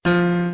Piano Keys C Scale New
f1.wav